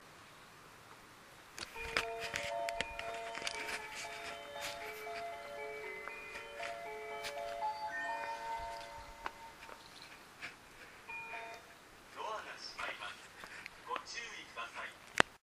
スピーカーは指向タイプのCLD 横長型が設置されています。音質は普通ですが音量は小さいので密着収録をしないと綺麗に録ることは難しいですね。
発車メロディー1.1コーラスです。上りの車掌が折り返してきたため再び長くなりました。